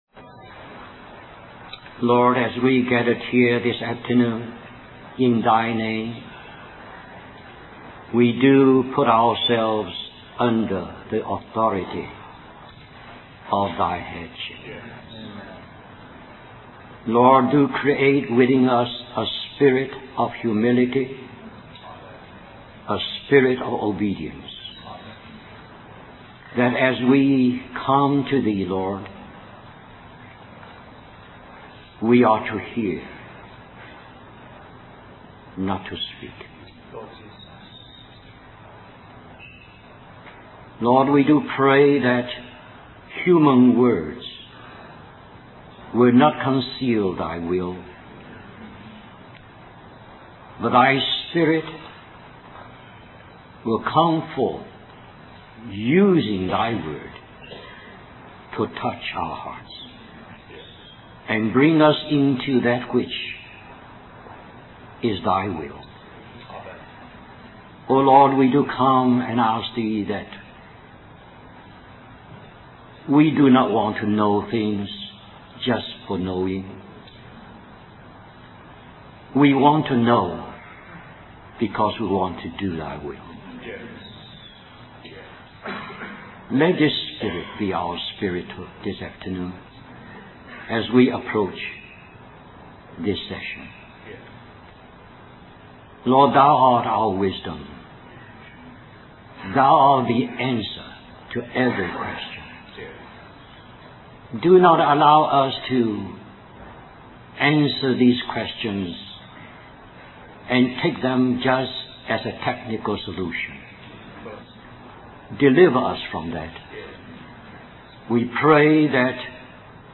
Question and Answer
Christian Family Conference